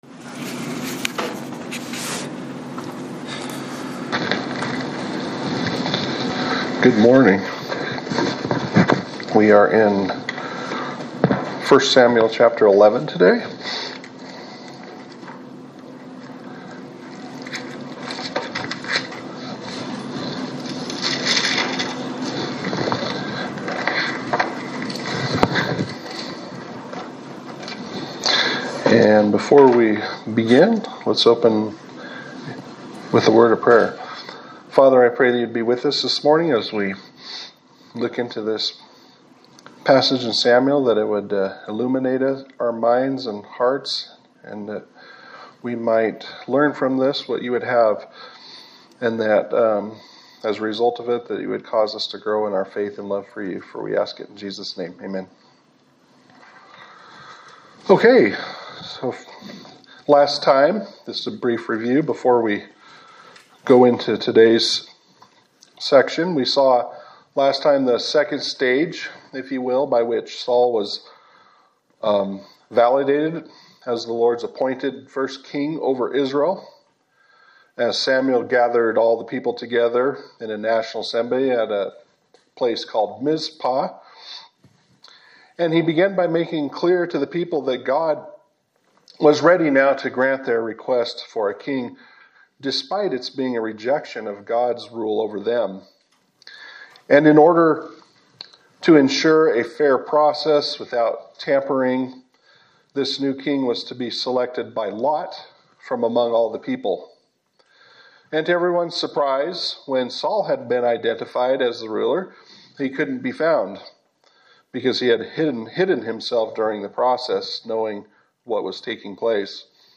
Sermon for September 7, 2025
Service Type: Sunday Service